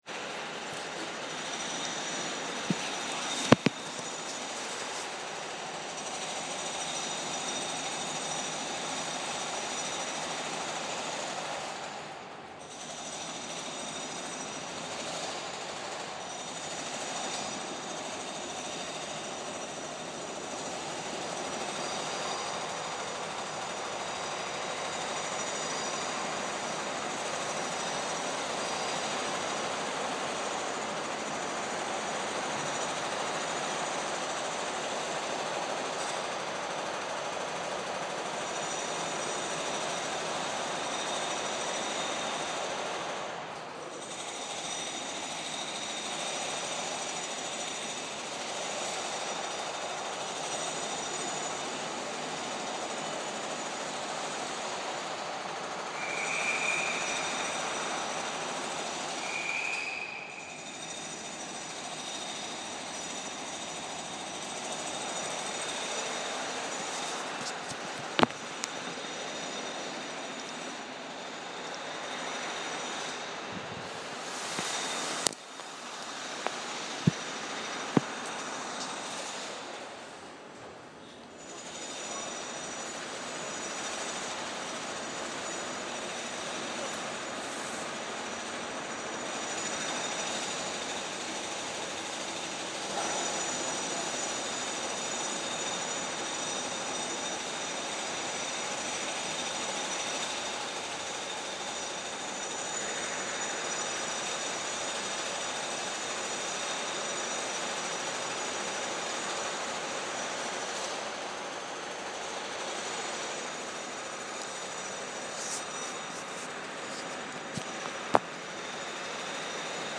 Midnight drilling echoing across the Waterloo station trainshed as I head home on the final train of the night